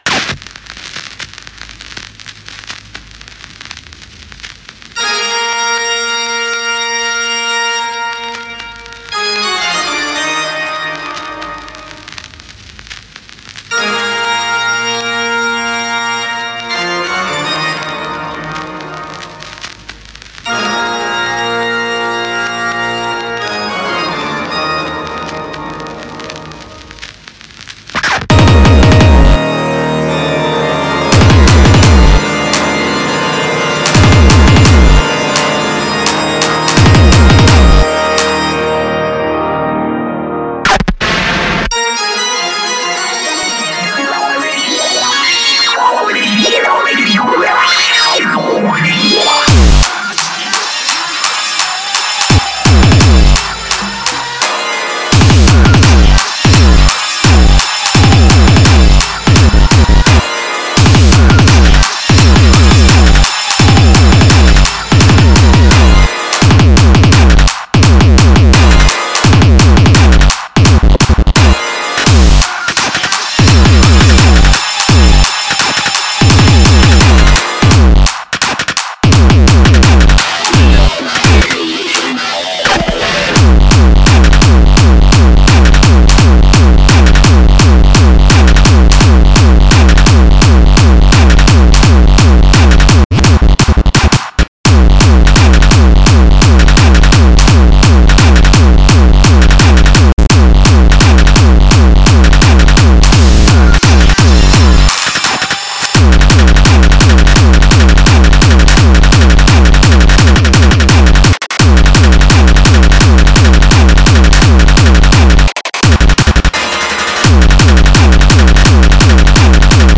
Genre: Death Metal